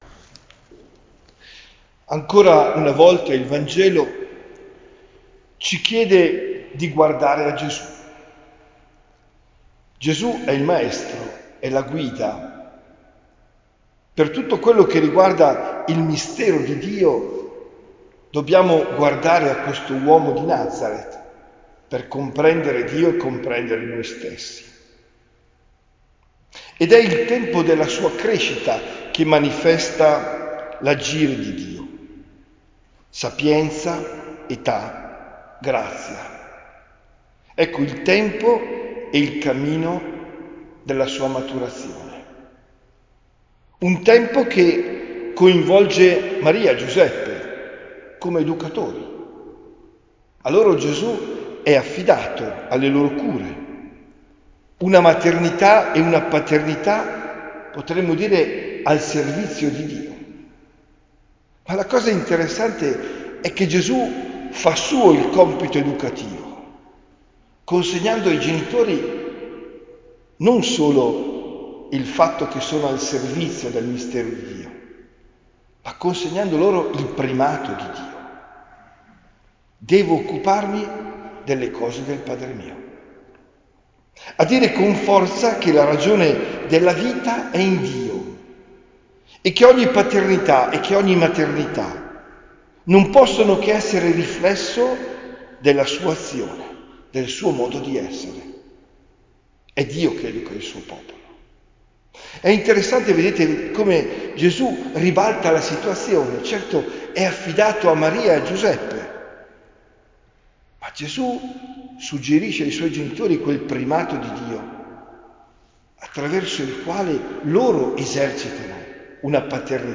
OMELIA DEL 29 DICEMBRE 2024